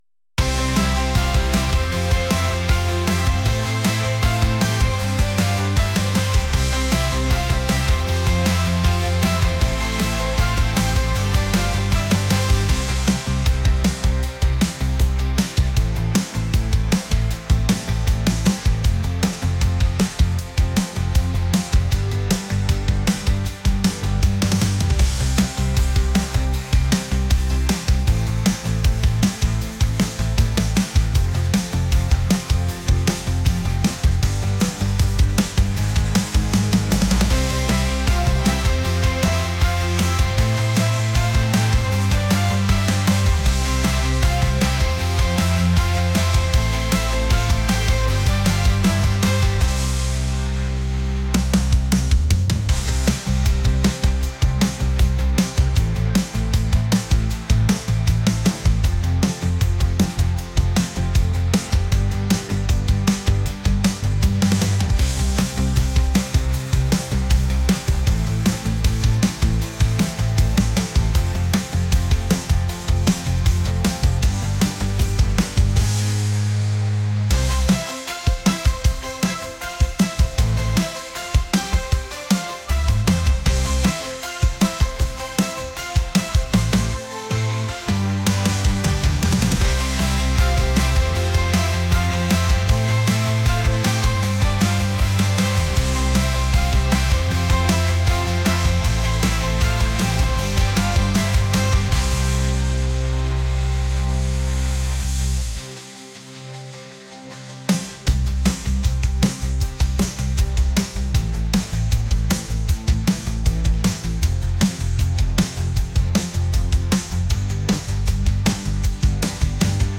energetic | upbeat | pop